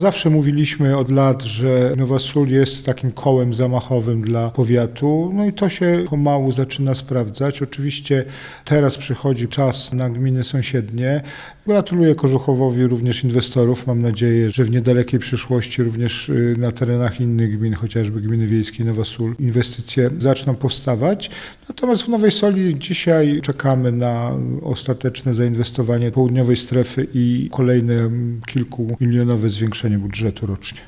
– Inwestorzy szukają nowych terenów pod inwestycje – powiedział Jacek Milewski, prezydent Nowej Soli: